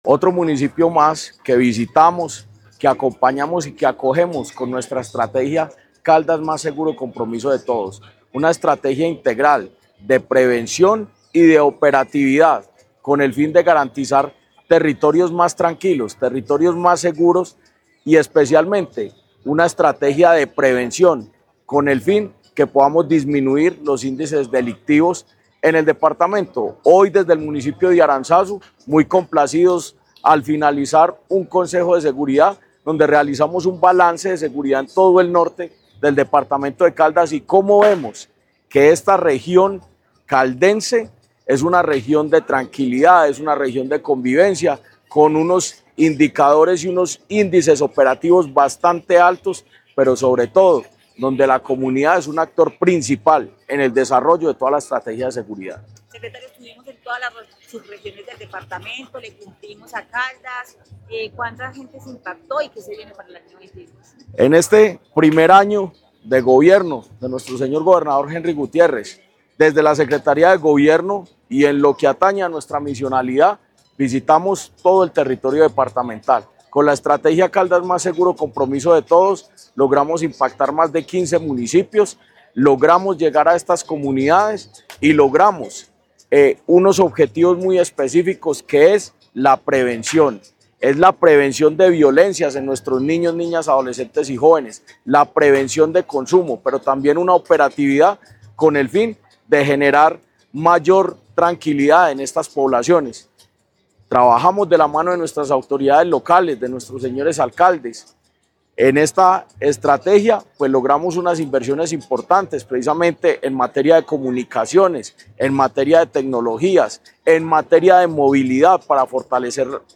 Secretario de Gobierno de Caldas, Jorge Andrés Gómez.